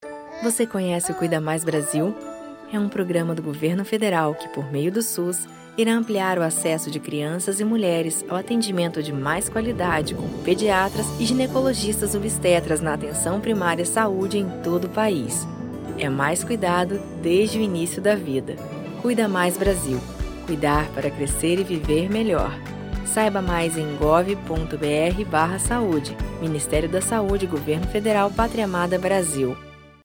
Spot - Cuida Mais Brasil